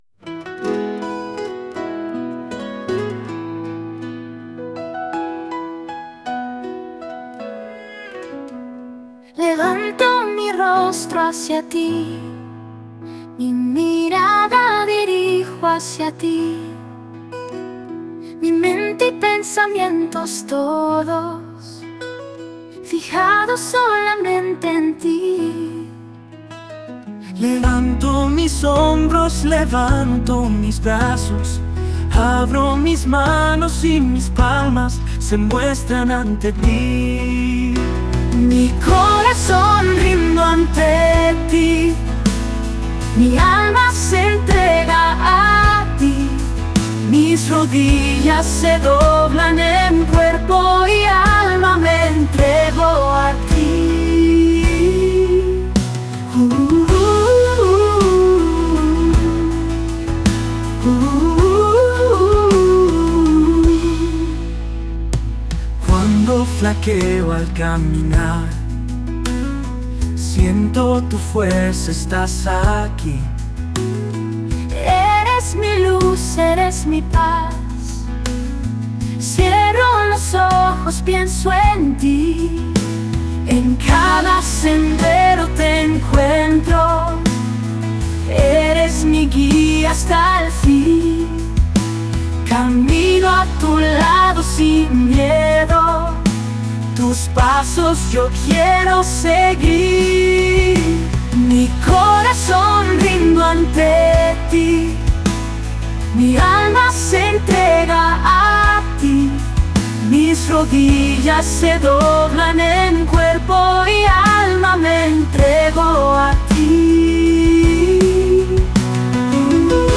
género Pop.
Pop